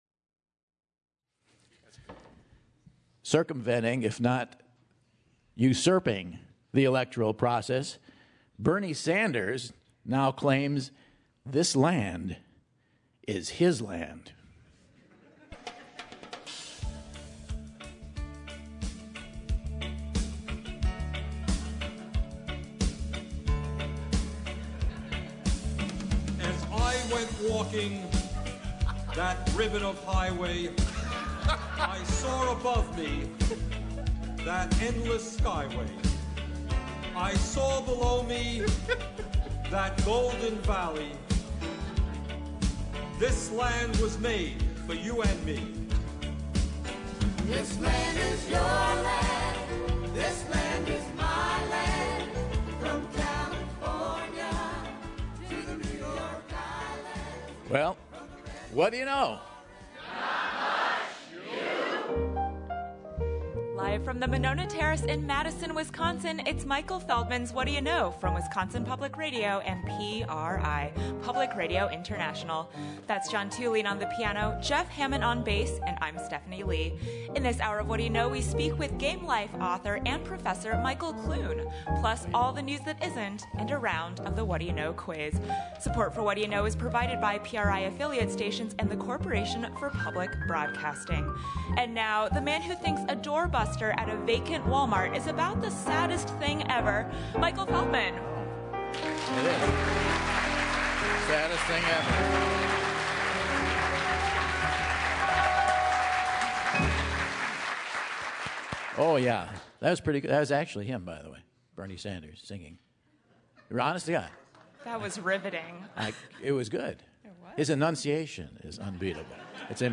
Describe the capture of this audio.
January 16, 2016 - Madison, WI - Monona Terrace | Whad'ya Know?